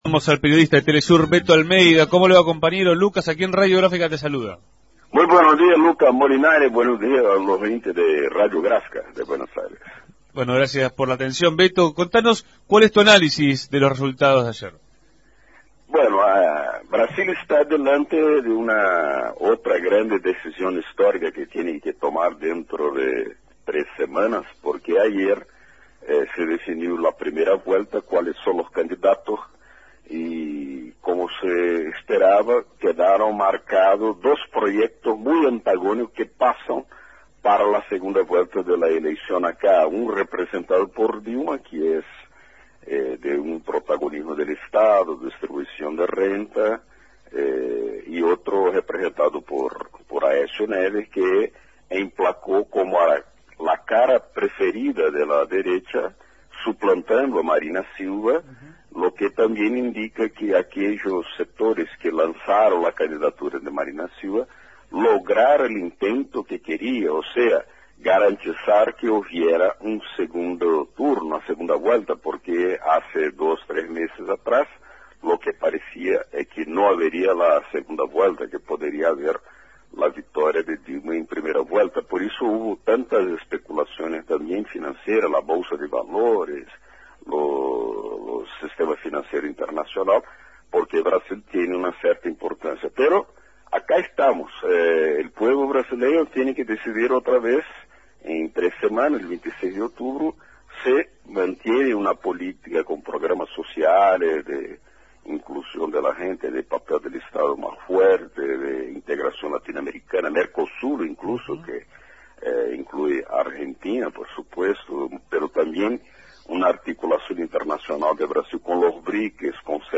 dialogó esta mañana en Punto de Partida